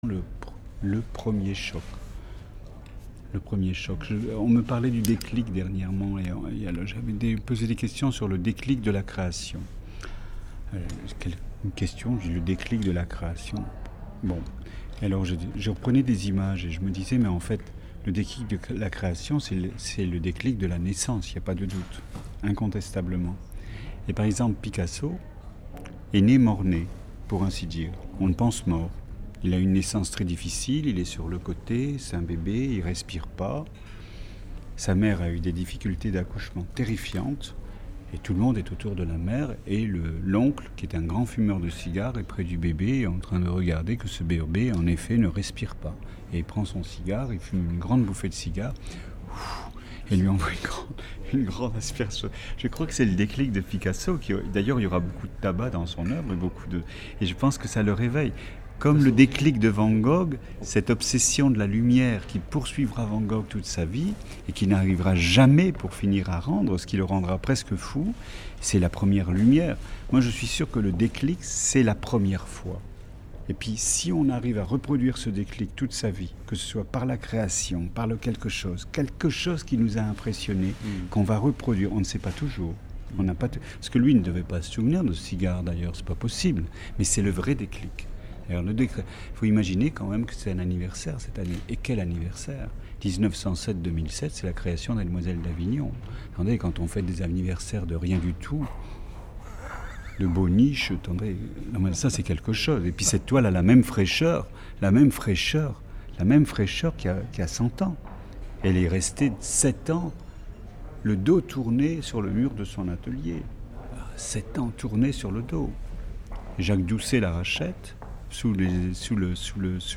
Première partie de l’interview de Serge Lutens consacré aux Demoiselles d’Avignon et à Picasso